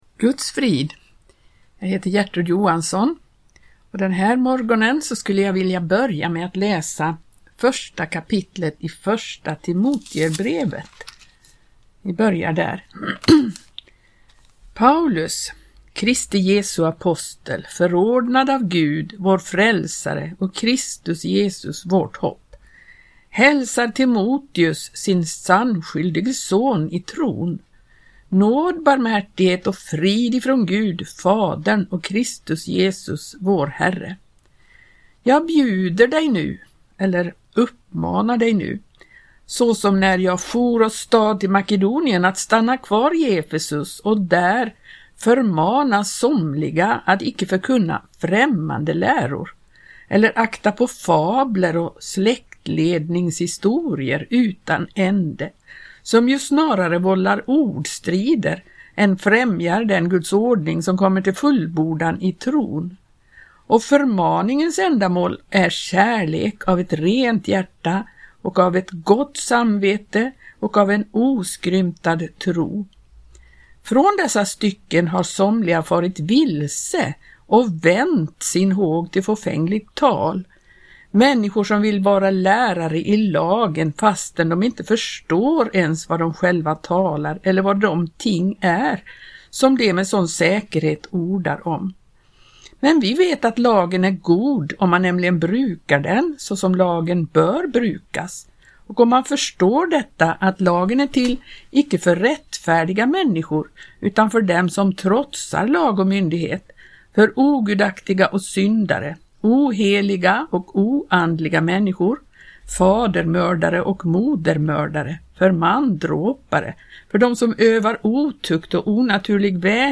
läser ur 1 Timoteusbrevet i Radio Maranata.